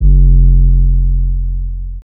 808_Itz Bussin.wav